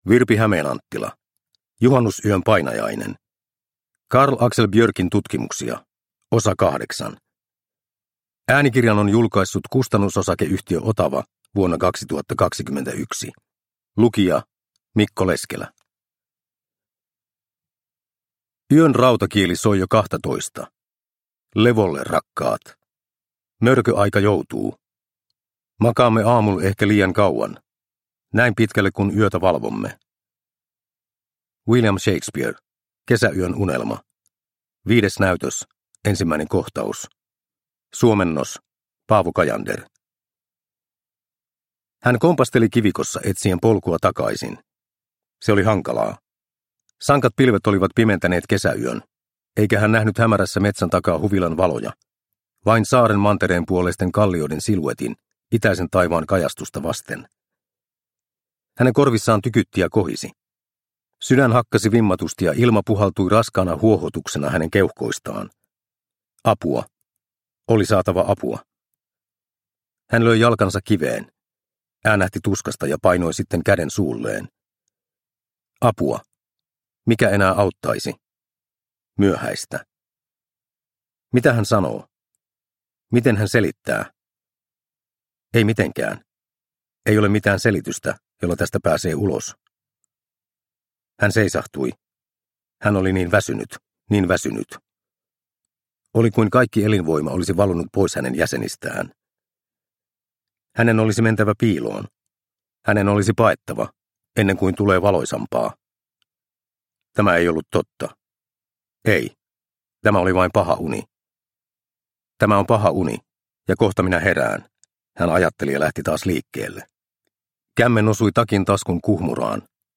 Juhannusyön painajainen – Ljudbok – Laddas ner